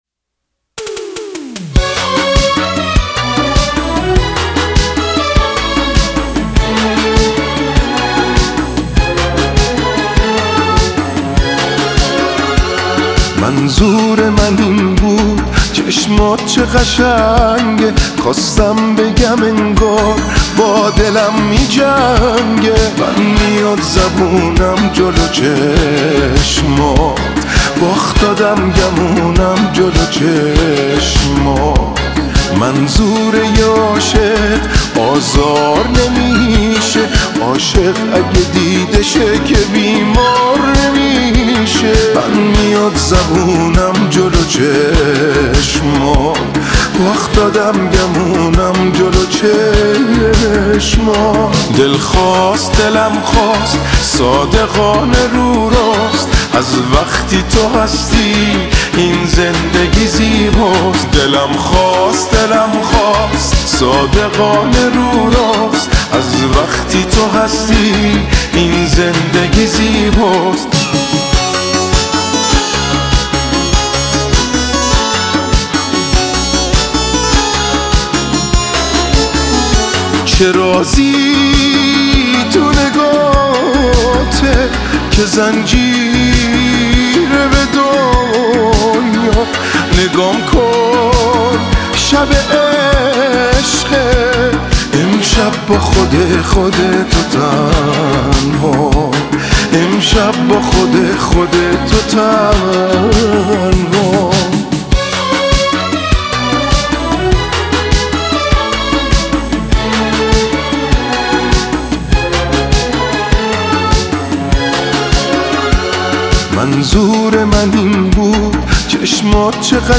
این سبک نوستالژی خیلی جذابه